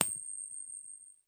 Coin Flung.wav